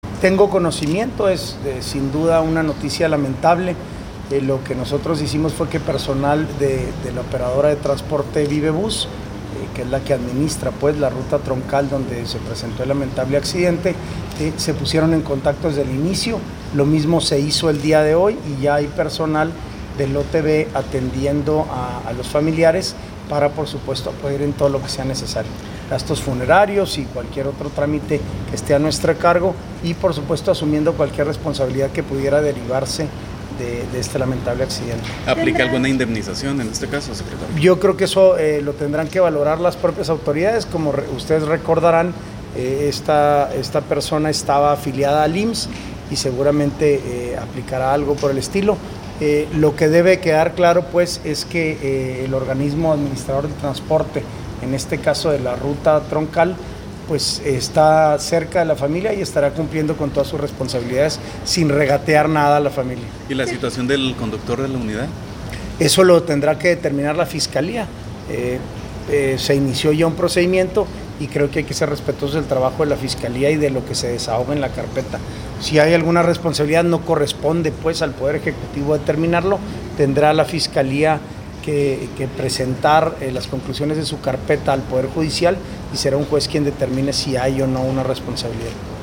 AUDIO: SANTIAGO DE LA PEÑA, SECRETARÍA GENERAL DE GOBIERNO (SGG)